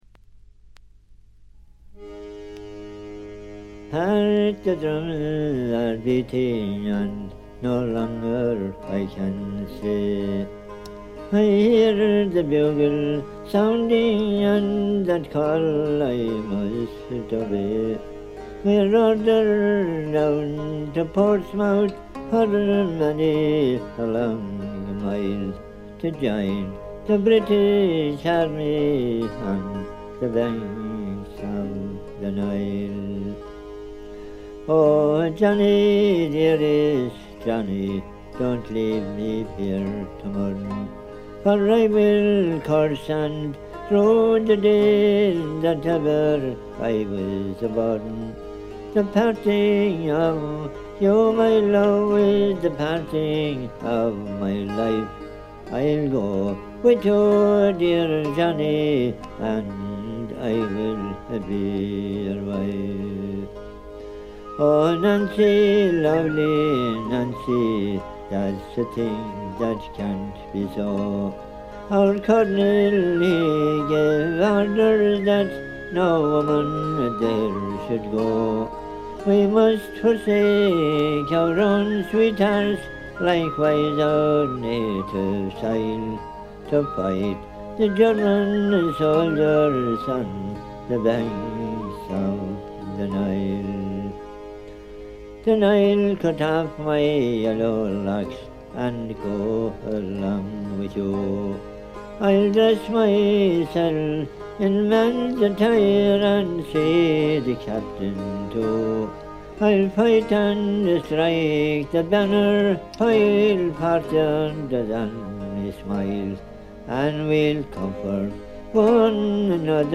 ごくわずかなノイズ感のみ。
売りである哀愁のアイリッシュムードもばっちり。
アイリッシュ・トラッド基本盤。
試聴曲は現品からの取り込み音源です。